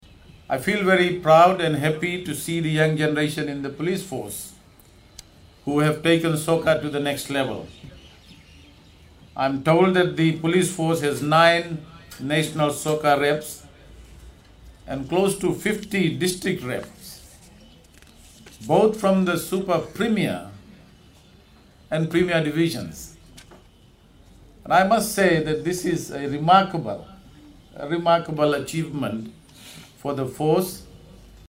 In his address at the opening of the Fiji Police Interformation Soccer Tournament at Subrail Park in Labasa today, Deputy Prime Minister Biman Prasad encouraged police officers to actively participate in sports to help maintain fitness.